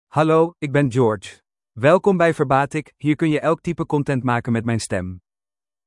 George — Male Dutch AI voice
George is a male AI voice for Dutch (Netherlands).
Voice sample
Listen to George's male Dutch voice.
George delivers clear pronunciation with authentic Netherlands Dutch intonation, making your content sound professionally produced.